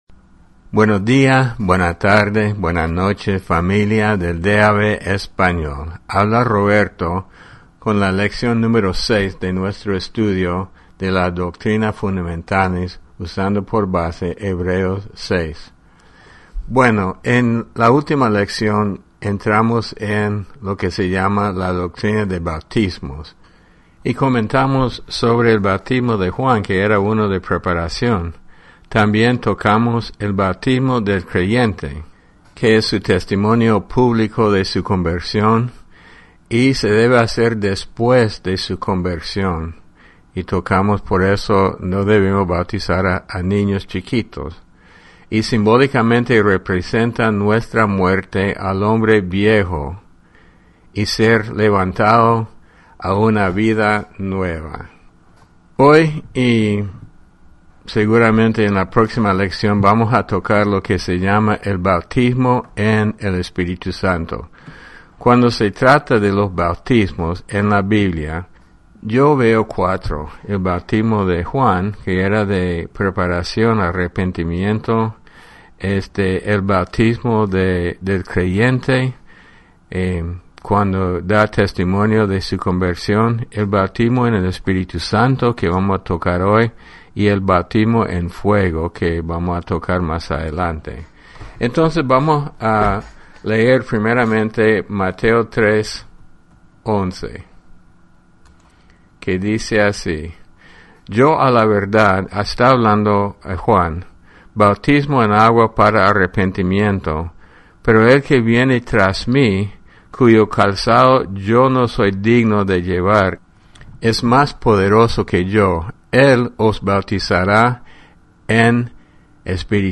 Descargar Audio Lección 06 Doctrinas fundamentales Repaso 1.